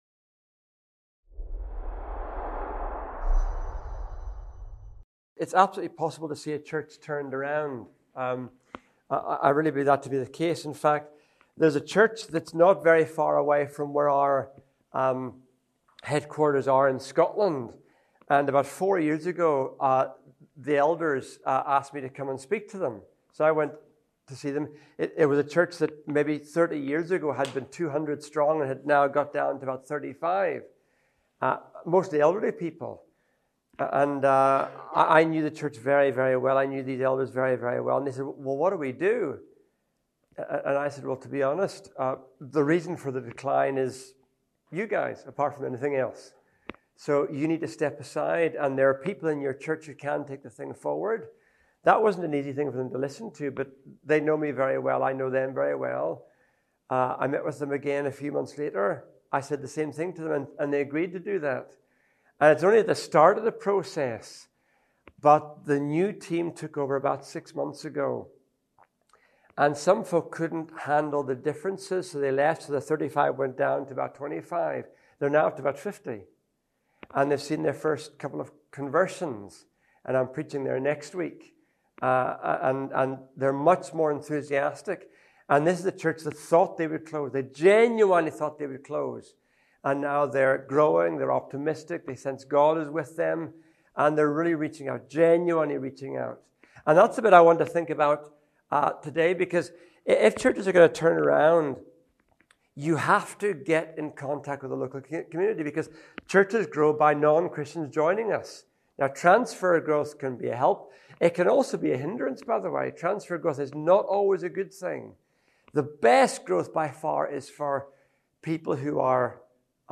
In this talk, we examine how genuine and caring relationships are the key for evangelism in our secular world.